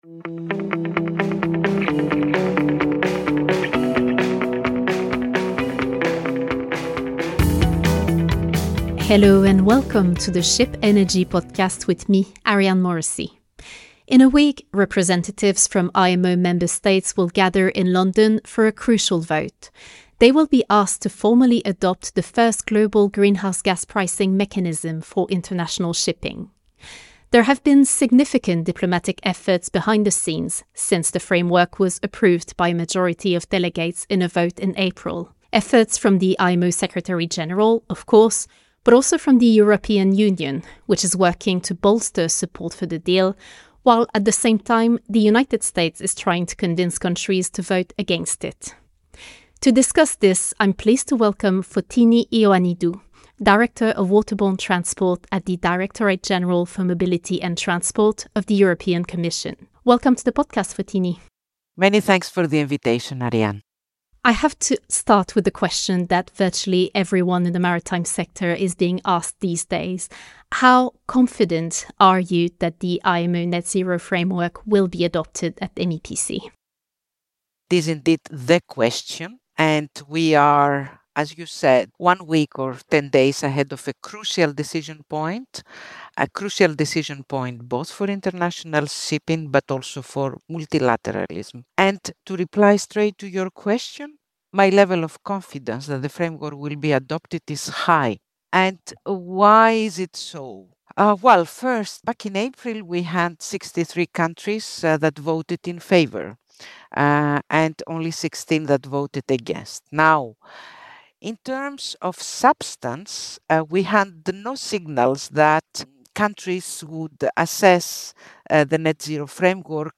Podcast highlights In this conversation, the EU Commission’s Director of Waterborne Transport, Fotini Ioannidou, explains why she remains confident that the IMO Net-Zero Framework will be adopted by a ‘large majority’ of member states at an extraordinary session of the organisation’s Marine Environment Protection Committee (MEPC) in mid-October.